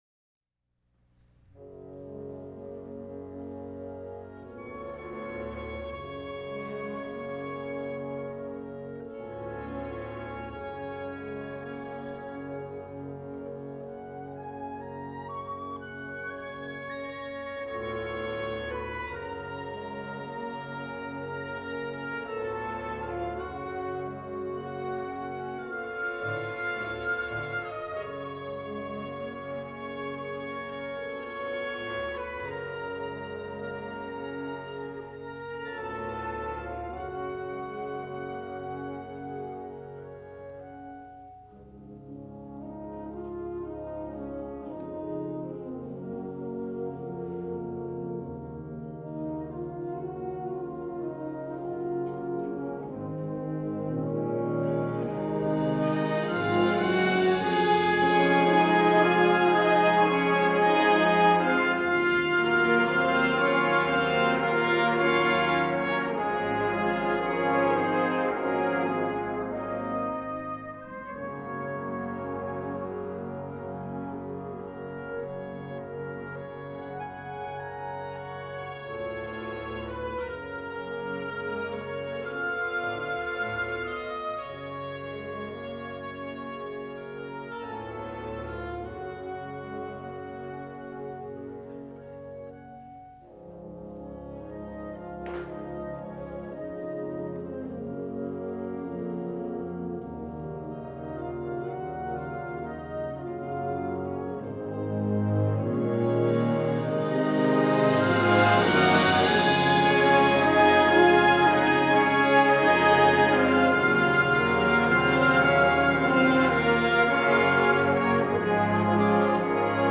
Genre: Band
Piccolo
Bb Clarinet 1
Eb Alto Saxophone 1
Bb Trumpet 1/2
F Horn 1
Euphonium
Tuba
Percussion 1 (Timpani)
Percussion 3 (Gong, Suspended Cymbal, Snare Drum, Bass Drum)